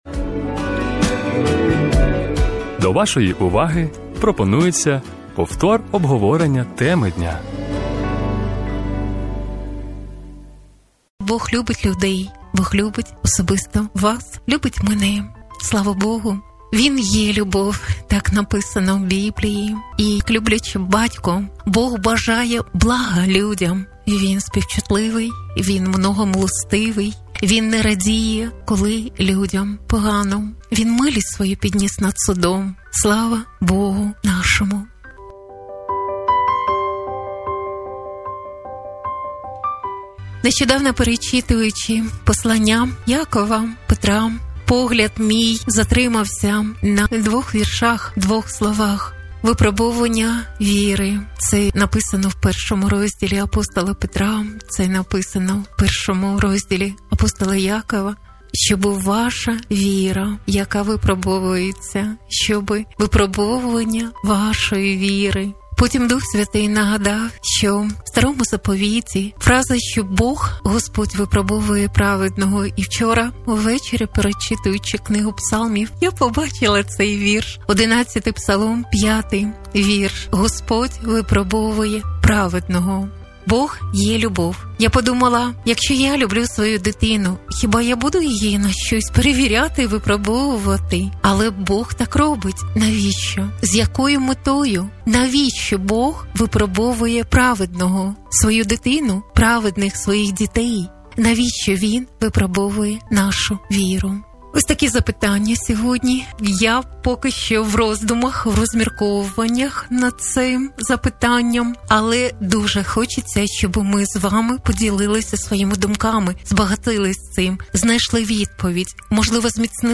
Зі слухачами розмірковували, З ЯКОЮ МЕТОЮ ГОСПОДЬ ВИПРОБОВУЄ ПРАВЕДНОГО,...